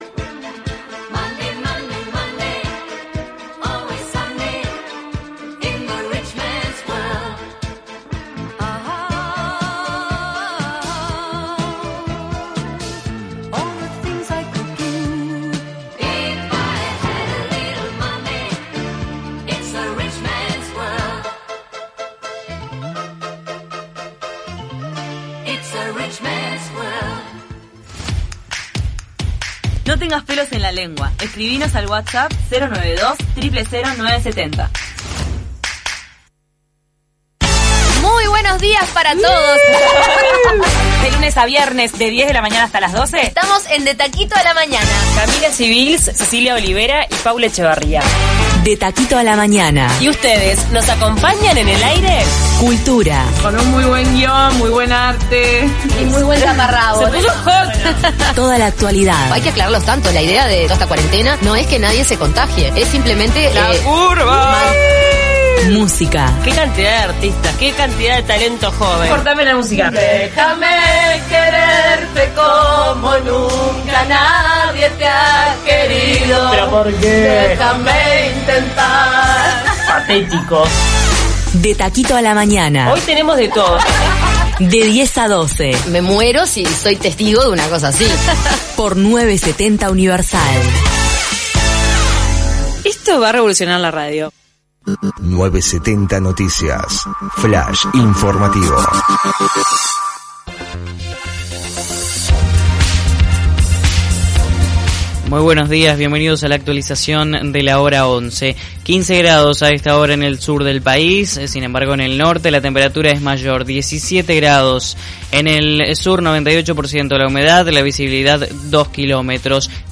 Para el análisis, De taquito a la mañana consultó el testimonio de dos rabinos